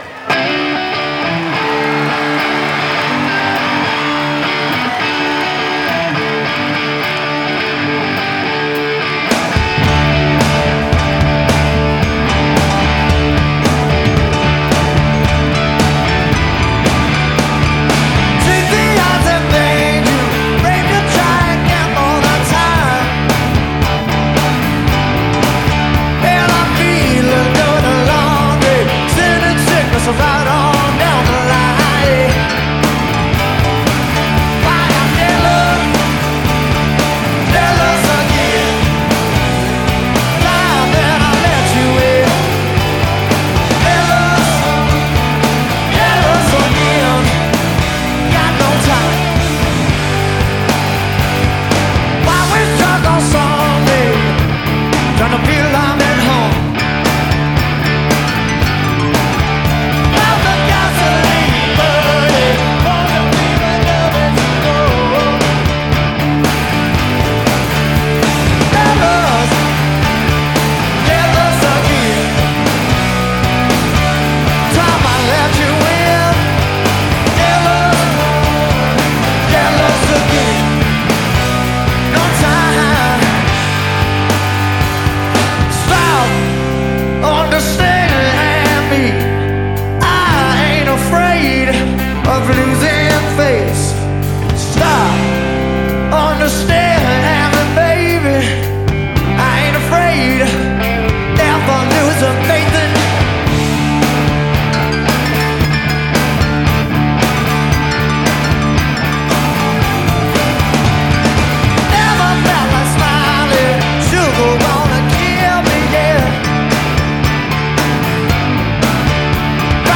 Live - Atlanta, December 1990